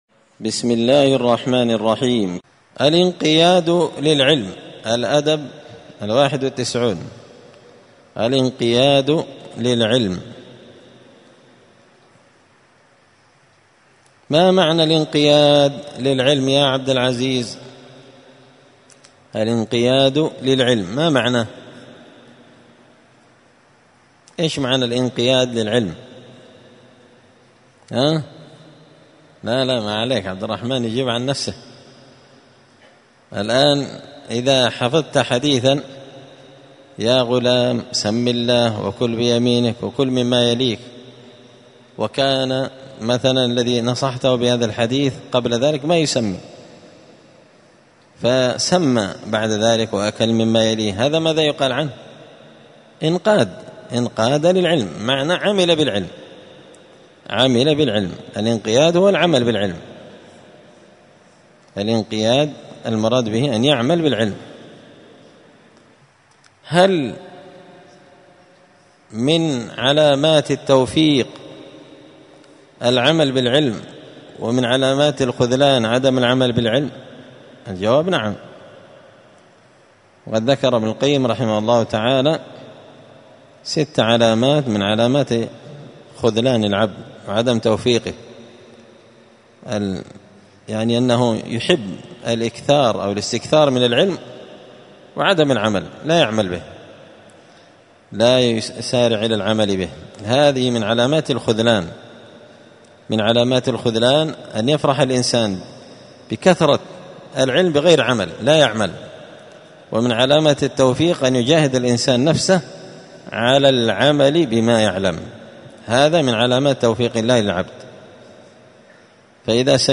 الجمعة 21 ربيع الأول 1445 هــــ | الدروس، النبذ في آداب طالب العلم، دروس الآداب | شارك بتعليقك | 106 المشاهدات
مسجد الفرقان قشن_المهرة_اليمن